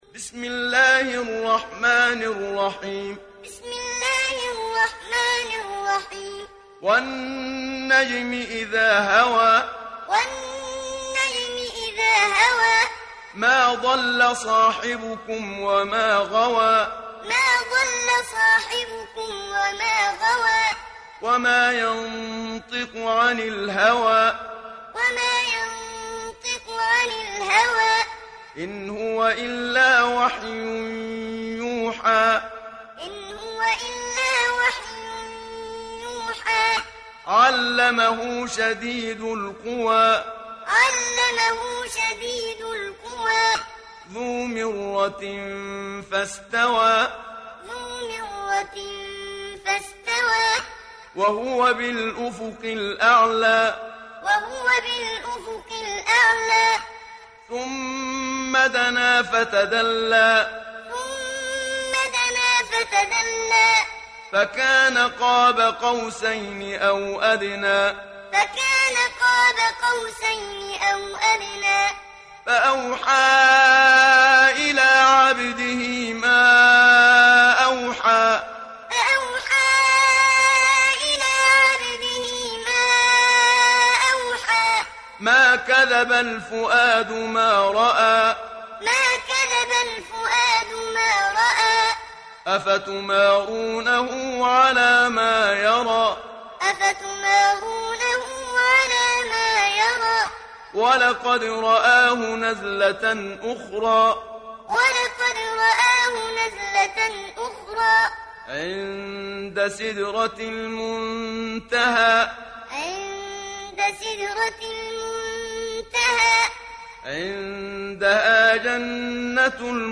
دانلود سوره النجم mp3 محمد صديق المنشاوي معلم روایت حفص از عاصم, قرآن را دانلود کنید و گوش کن mp3 ، لینک مستقیم کامل
دانلود سوره النجم محمد صديق المنشاوي معلم